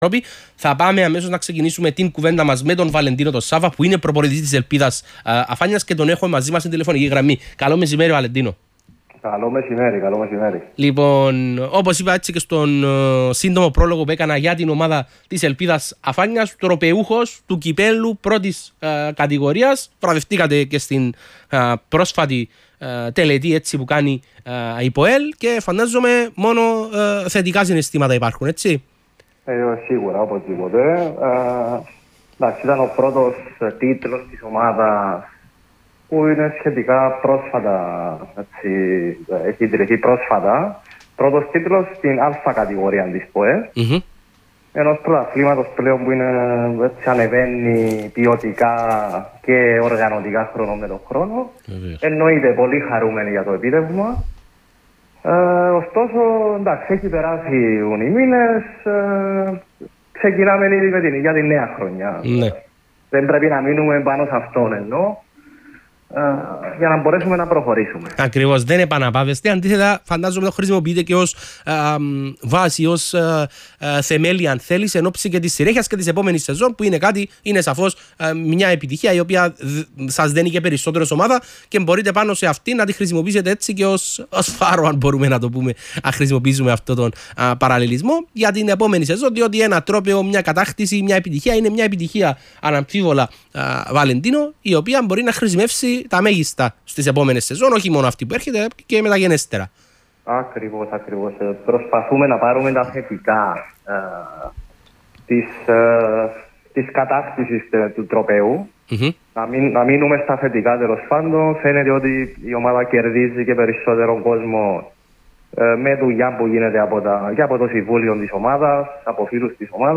ραδιοφωνικές δηλώσεις